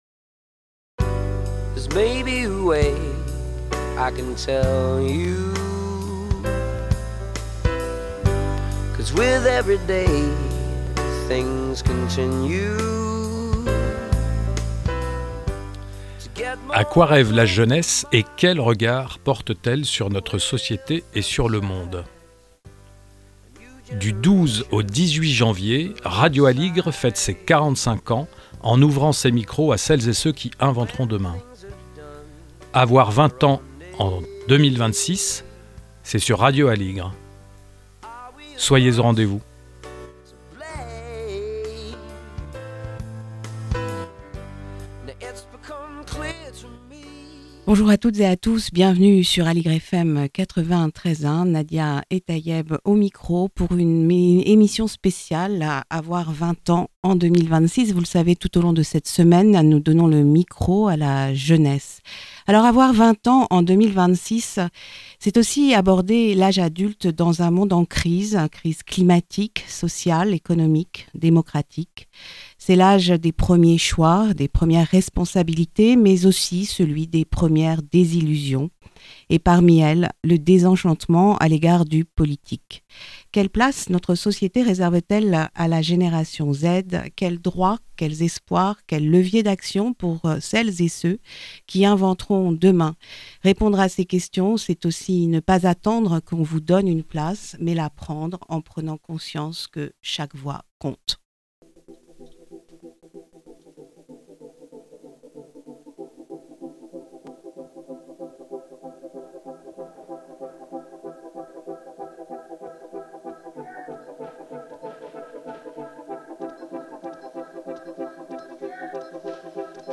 A l'occasion de ses 45 ans, Aligre FM donne la parole aux jeunes !